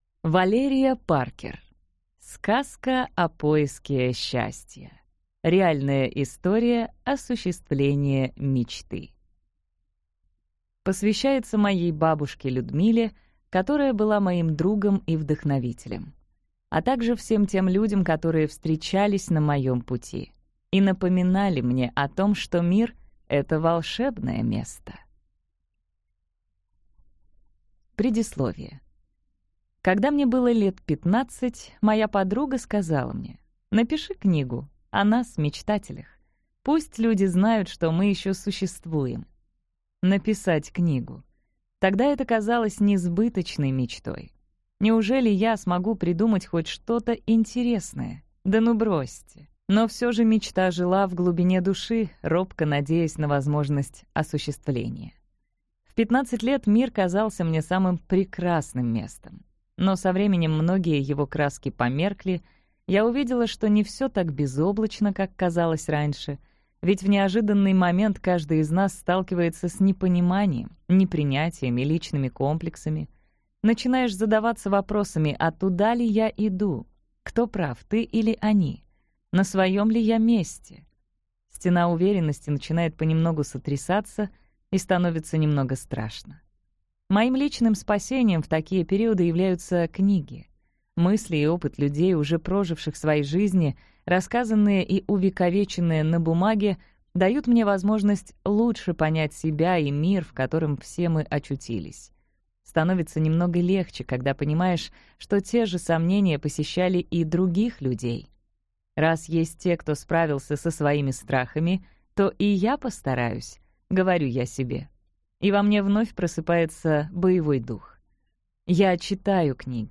Аудиокнига Сказка о поиске счастья. Реальная история осуществления мечты | Библиотека аудиокниг